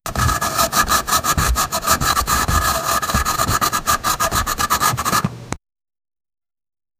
Chalkboard Sound Effects - Free AI Generator & Downloads